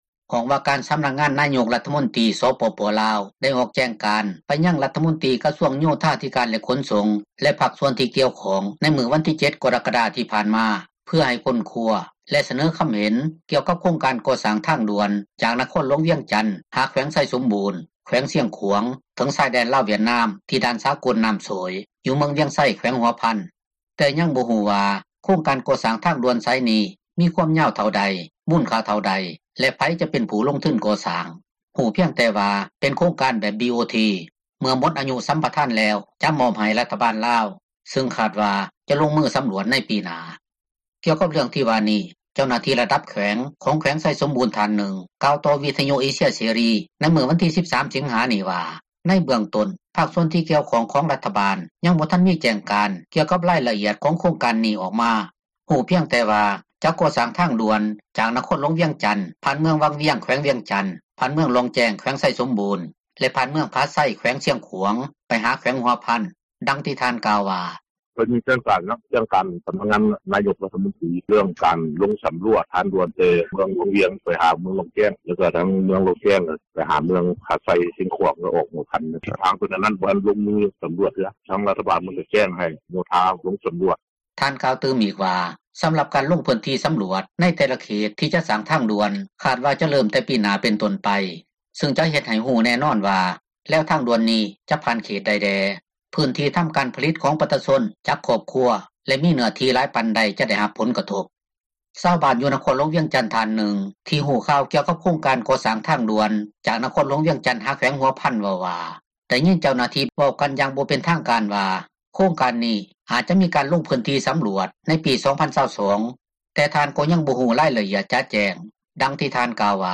ກ່ຽວກັບເຣື່ອງທີ່ວ່ານີ້ ເຈົ້າໜ້າທີ່ຣະດັບ ແຂວງໄຊສົມບູນ ທ່ານນຶ່ງກ່າວຕໍ່ວິທຍຸເອເຊັຽເສຣີ ໃນມື້ວັນທີ 13 ສິງຫາ ນີ້ວ່າເບື້ອງຕົ້ນ ພາກສ່ວນທີ່ກ່ຽວຂ້ອງ ຍັງບໍ່ມີແຈ້ງການ ກ່ຽວກັບ ຣາຍລະອຽດ ຂອງໂຄງການນີ້ອອກມາ, ຮູ້ພຽງແຕ່ວ່າຈະກໍ່ສ້າງ ທາງດ່ວນຈາກ ນະຄອນຫລວງວຽງຈັນ ຜ່ານເມືອງວັງວຽງ ແຂວງວຽງຈັນ, ຜ່ານເມືອງລ່ອງແຈ້ງ ແຂວງໄຊສົມບູນ ແລະ ຜ່ານເມືອງຜາໄຊ ແຂວງຊຽງຂວາງ ໄປຫາແຂວງຫົວພັນ ດັ່ງທີ່ທ່ານກ່າວວ່າ:
ທີ່ແຂວງຊຽງຂວາງ ຊາວບ້ານທ່ານນຶ່ງ ກໍກ່າວໂດຍເຊື່ອວ່າ ໂຄງການກໍ່ສ້າງທາງດ່ວນດັ່ງກ່າວ ຈະຕັດຜ່ານເຂດປ່າແລະພູຜາ ຫລາຍກວ່າເຂດທີ່ ປະຊາຊົນຢູ່ອາສັຍ ເນື່ອງຈາກພື້ນທີ່ ທີ່ເປັນຮອຍຕໍ່ ຣະຫວ່າງ ແຂວງໄຊສົມບູນ ແລະແຂວງຊຽງຂວາງ ສ່ວນຫລາຍເປັນເຂດພູຜາ: